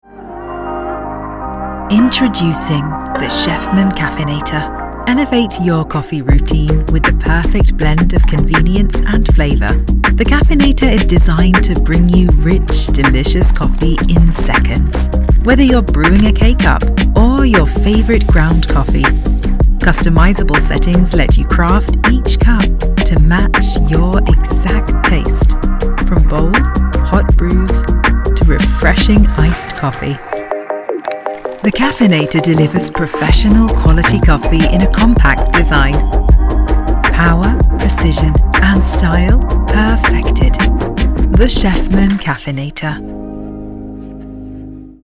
¡Hola! Soy un actor de doblaje galardonado que hablo inglés con fluidez (con acento británico y árabe) y árabe...
Confiable
Autoritario
Confiado